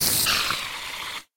mob / spider / death.ogg
death.ogg